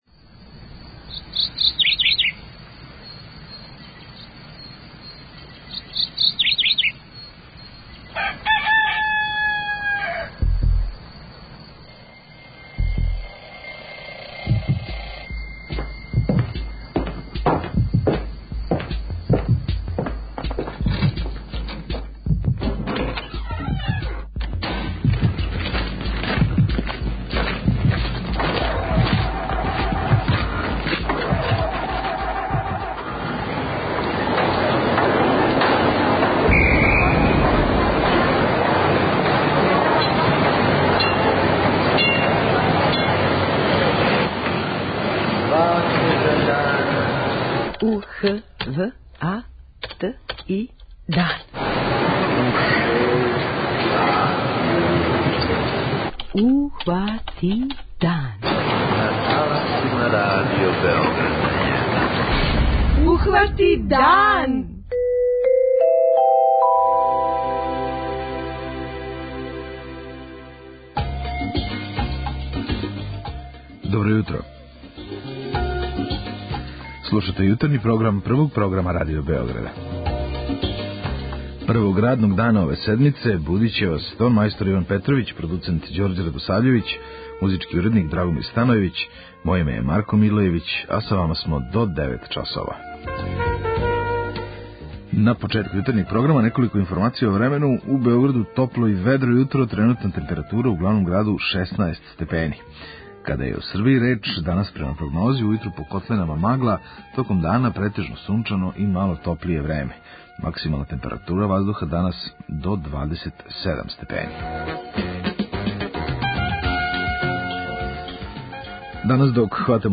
У оквиру емисије емитујемо: 06:03 Јутарњи дневник; 06:35 Догодило се на данашњи дан; 07:00 Вести; 07:05 Добро јутро децо; 08:00 Вести; 08:10 Српски на српском
преузми : 21.57 MB Ухвати дан Autor: Група аутора Јутарњи програм Радио Београда 1!